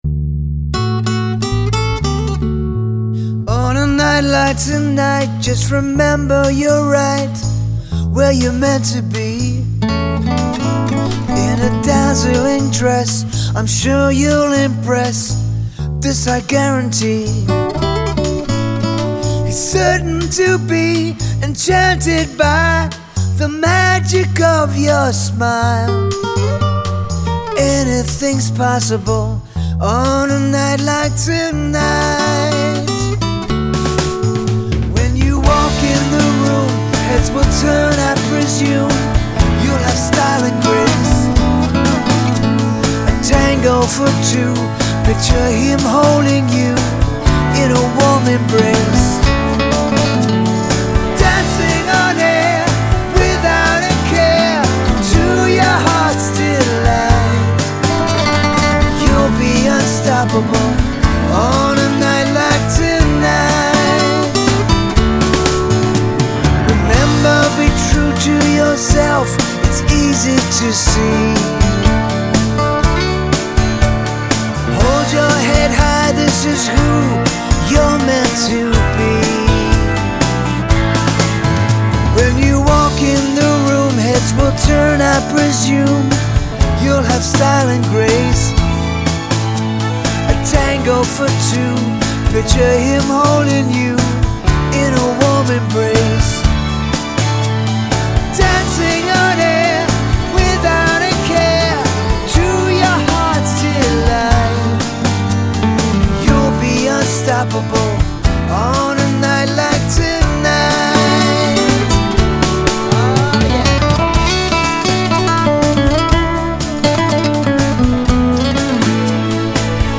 Genre : Comédies musicales